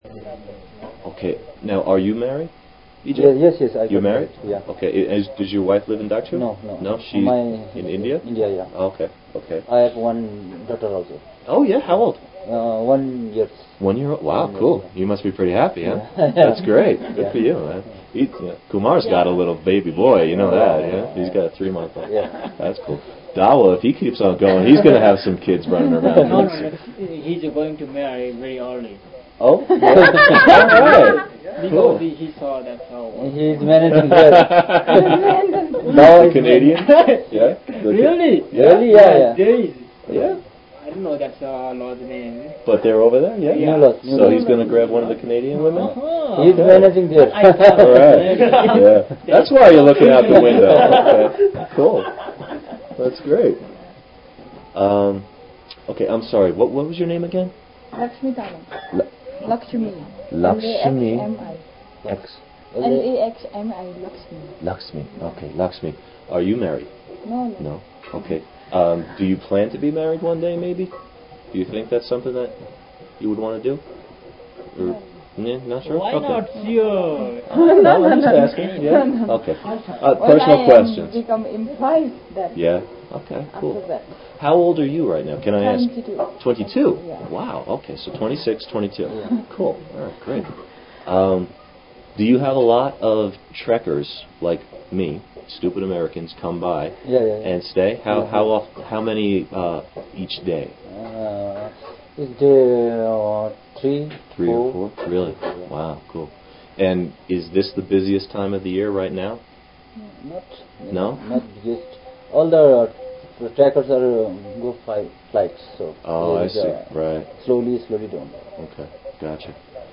Interview with lodge owners in Dagchu- part 2